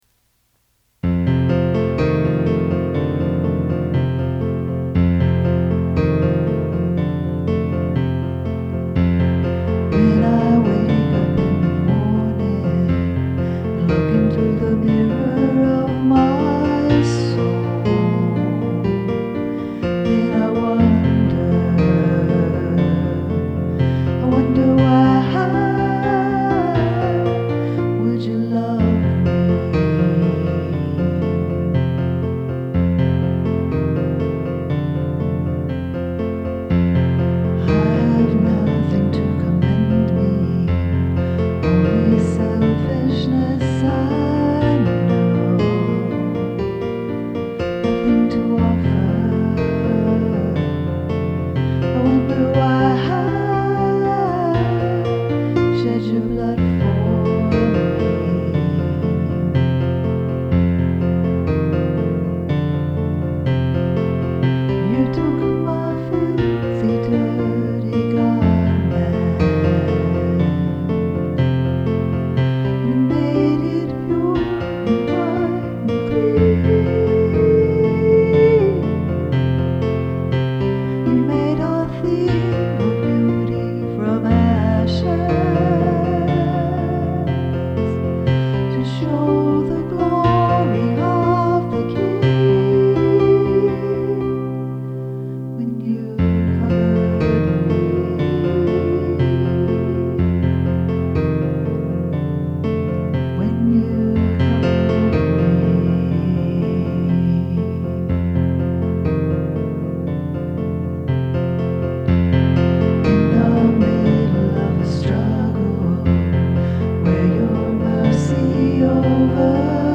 Piano
Vocals